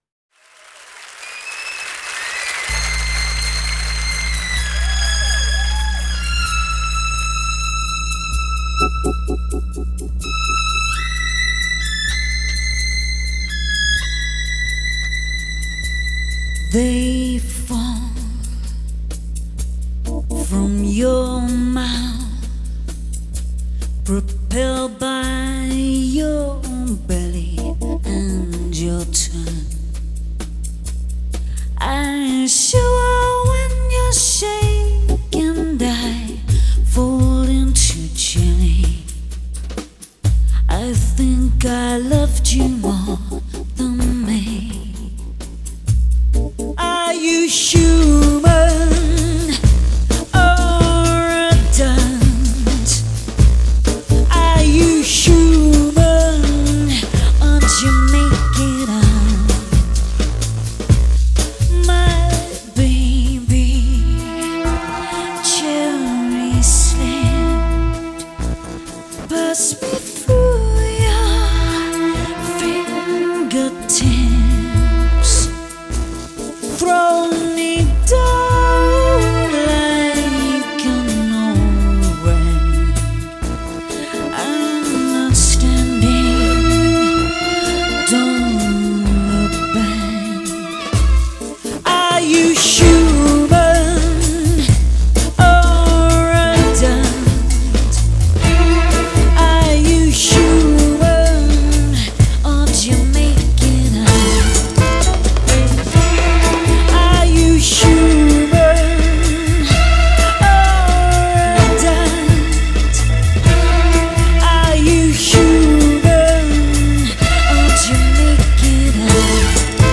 Genre: Electronic
Style: Leftfield, Downtempo